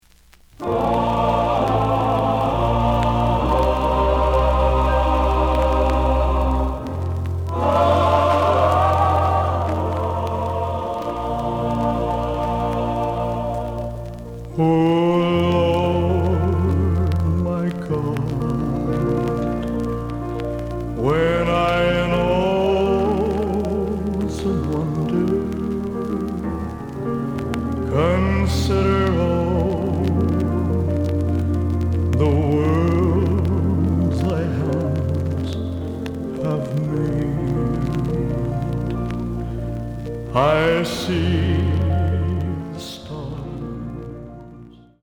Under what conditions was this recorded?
The audio sample is recorded from the actual item. Looks good, but slight noise on both sides.)